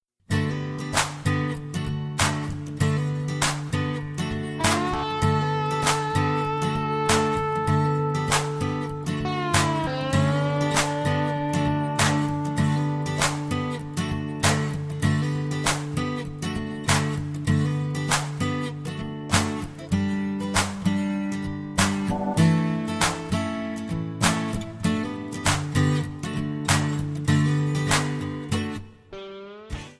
D
MPEG 1 Layer 3 (Stereo)
Backing track Karaoke
Country, 2000s